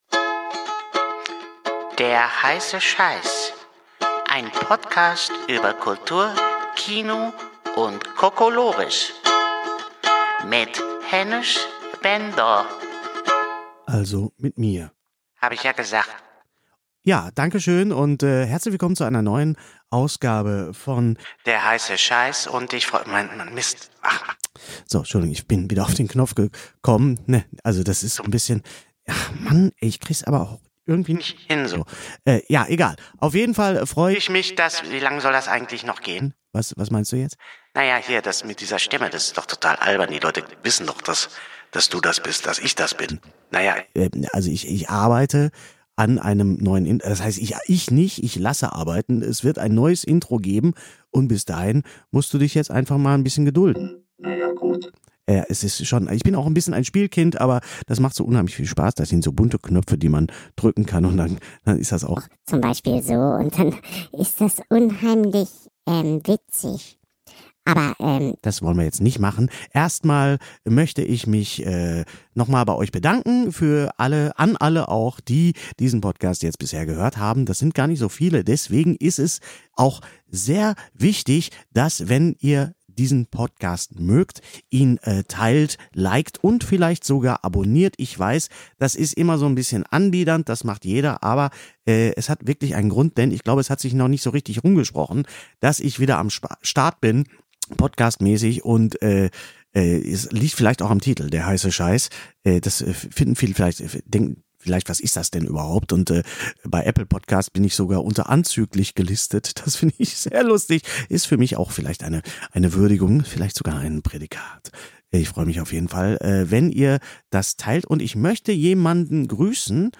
Podcast-Pionier Hennes Bender („Sträter Bender Streberg“) präsentiert seinen ersten Solo-Podcast und redet mit interessanten Gästen aus dem Bereich Kino, Comedy, Theater, Comic, Hörspiel und Kunst vor und hinter den Kulissen nicht nur über den aktuellsten „heissen Scheiss“, also die neuesten Filme, Serien, Bücher und Bühnenproduktionen, sondern auch über den derzeitigen Zustand von Kunst und Kultur. Immer aktuell, immer akkurat und immer irgendwie nerdig mit Fachwissen, hohem Unterhaltungswert, Wertschätzung und Leidenschaft.